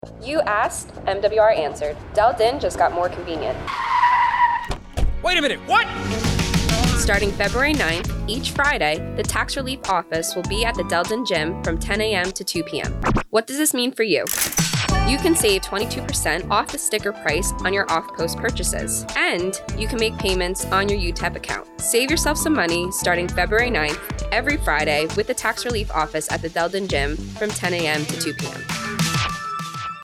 radio commercial announcing the Tax Relief Office's new location and hours on Caserma Del Din